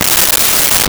18 Wheeler Air Brakes 01
18 Wheeler Air Brakes 01.wav